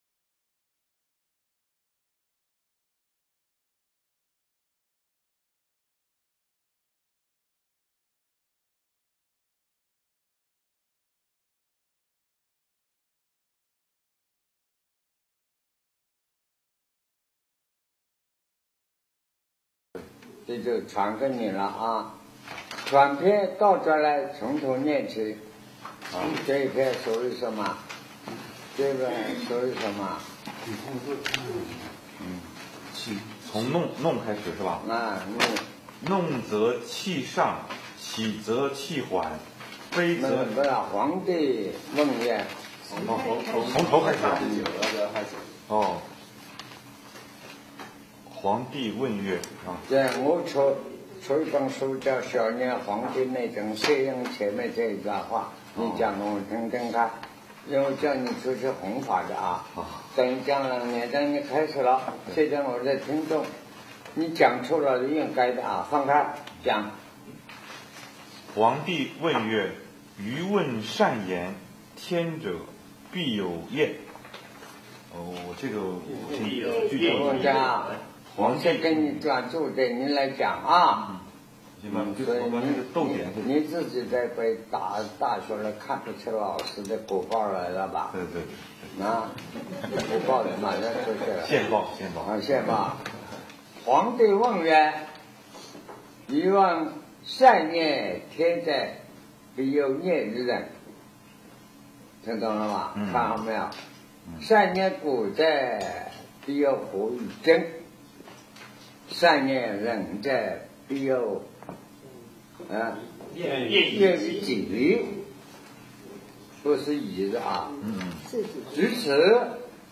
南師講《黃帝內經·舉痛論》02